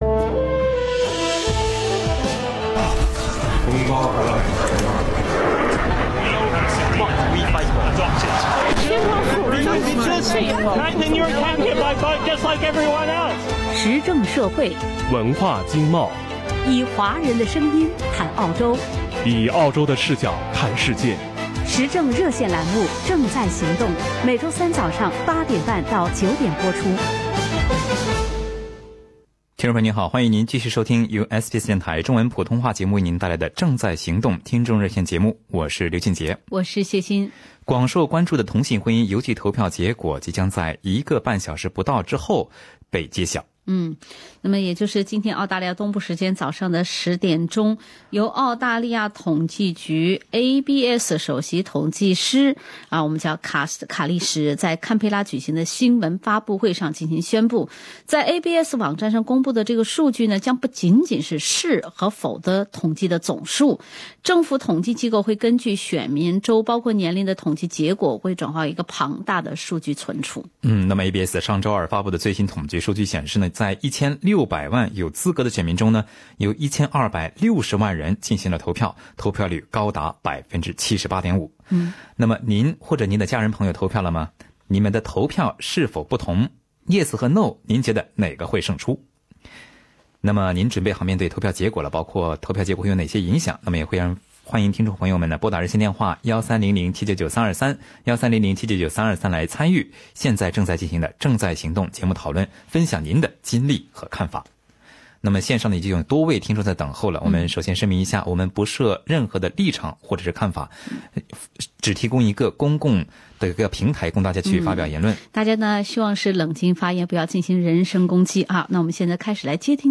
同性婚姻邮寄投票结果揭晓 你或者家人朋友投票了么？你们的投票是否不同？你觉得投票结果会有哪些影响？ 听众朋友们在本期《正在行动》节目中分享了自己的经历和看法。